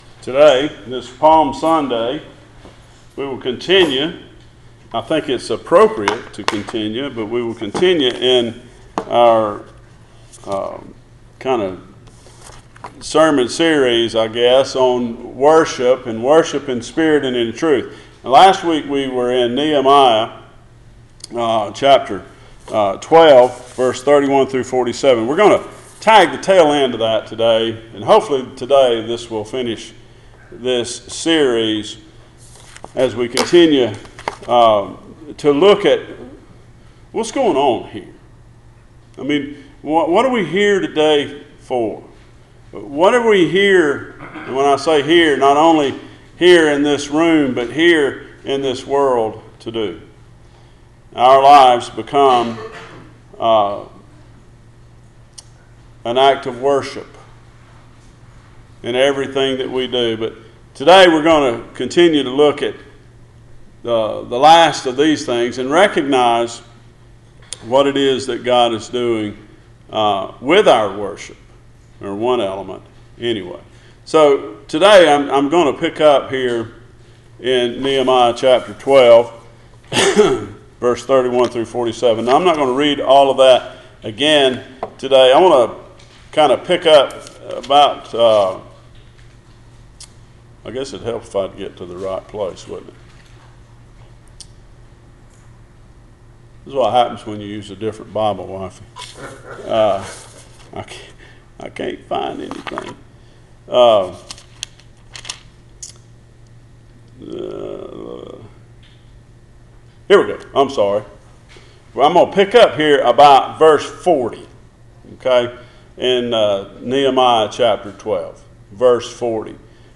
Passage: Nehemiah 12:31-47 Service Type: Sunday Morning